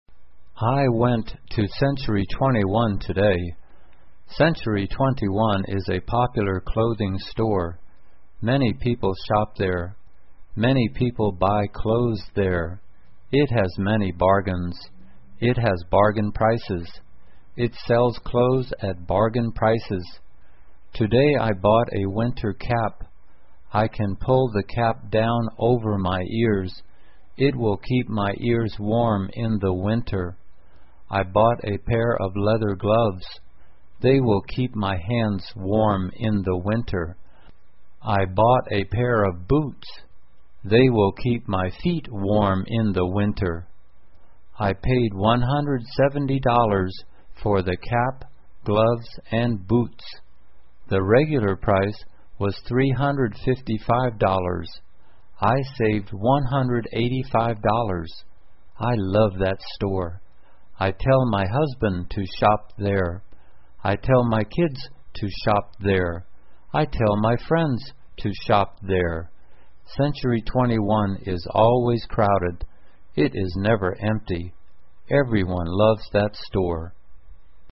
慢速英语短文听力 在纽约市购物 听力文件下载—在线英语听力室